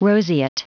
Prononciation du mot : roseate